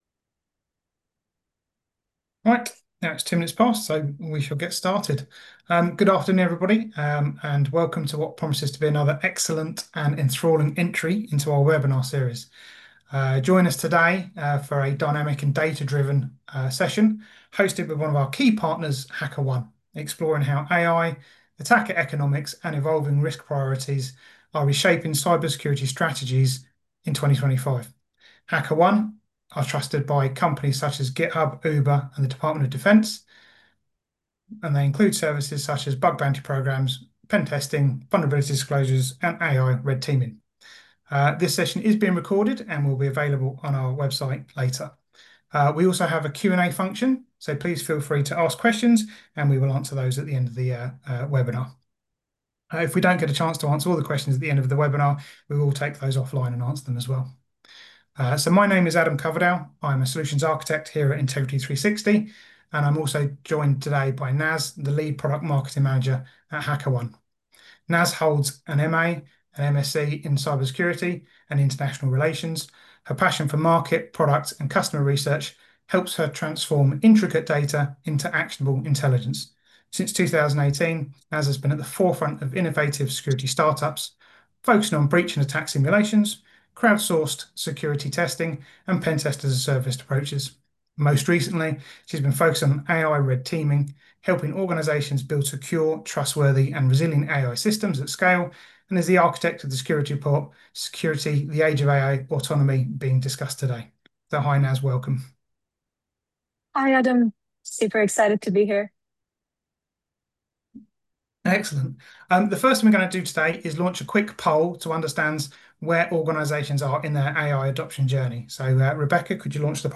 Integrity360 | Webinar | HackerOne | Reshaping cyber defence